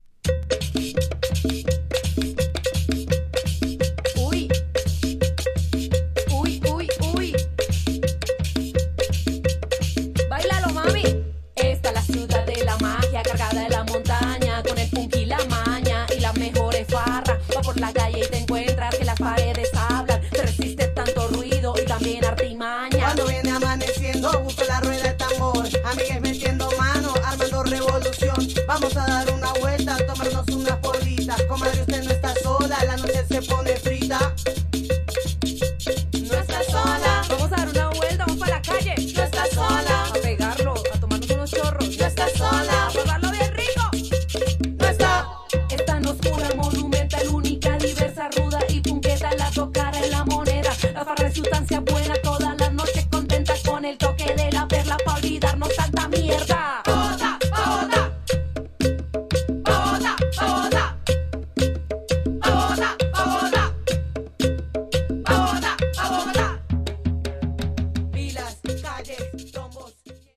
Tags: Bogotá , Japan , Folklorico